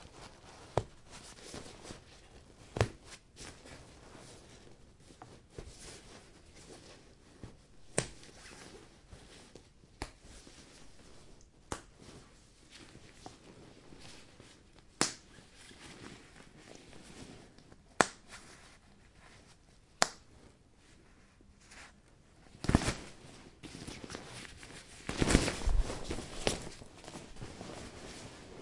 描述：在这里，我试图收集我在家里发现的所有扣件。其中大部分在夹克衫上，一个手提包里有啷个球，还有一些雪裤。
Tag: 点击 服装和-配件 扣紧固件